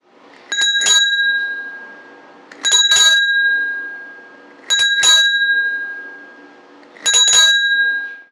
나도_모르게_딸랑.mp3